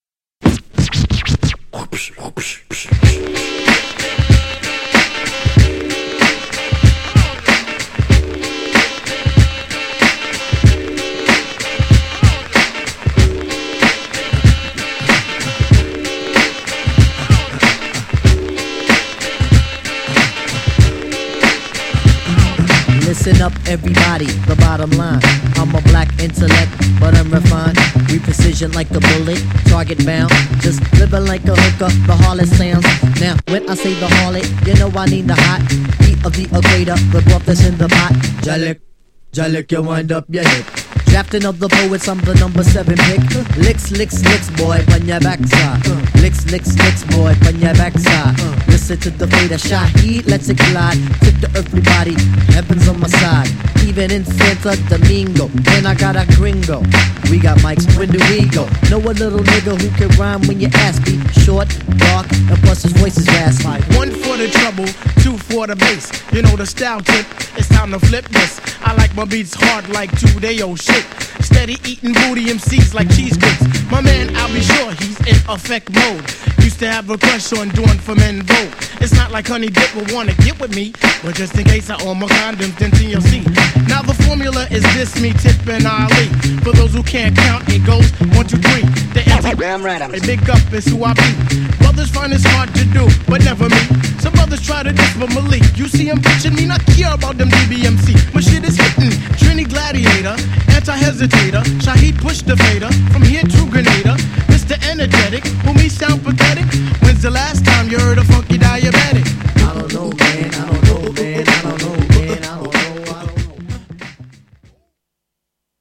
GENRE Hip Hop
BPM 96〜100BPM